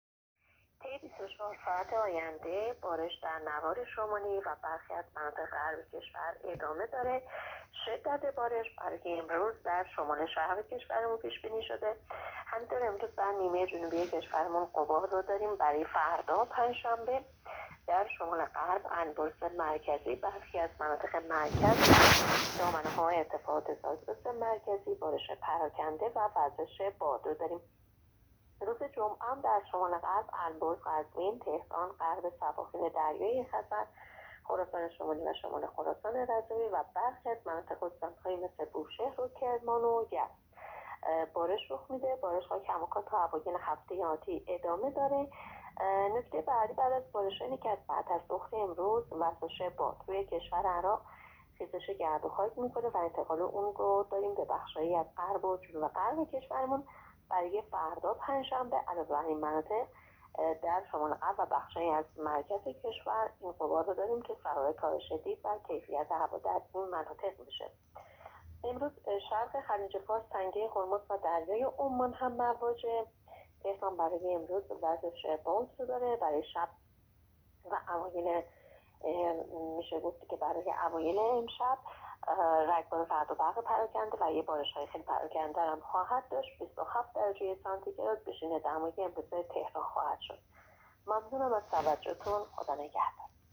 کارشناس سازمان هواشناسی در گفت‌وگو با رادیو اینترنتی پایگاه خبری وزارت راه‌ و شهرسازی، آخرین وضعیت آب‌و‌هوای کشور را تشریح کرد.
گزارش رادیو اینترنتی پایگاه خبری از آخرین وضعیت آب‌‌و‌‌‌هوای چهاردهم اردیبهشت؛